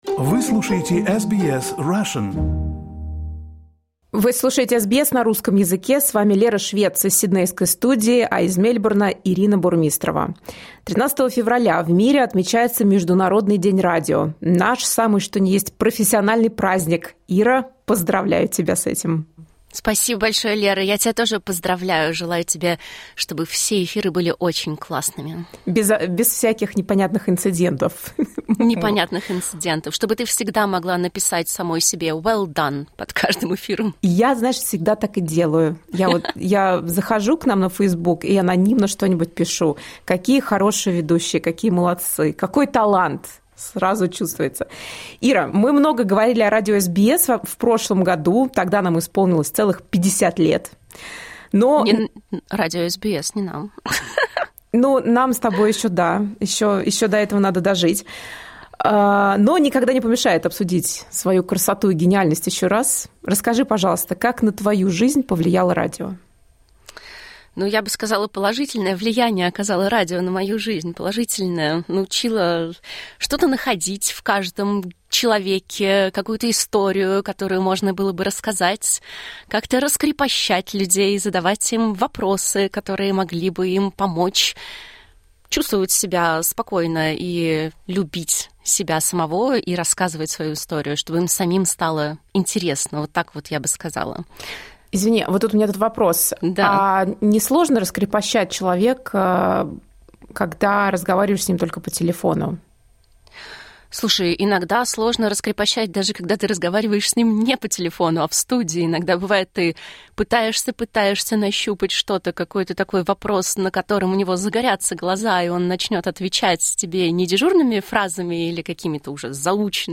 13 февраля отмечается Всемирный день радио. В свой профессиональный праздник продюсеры SBS Russian обсуждают, как радио изменило их жизнь и каких ошибок в эфире они боятся больше всего.